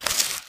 MISC Newspaper, Scrape 02.wav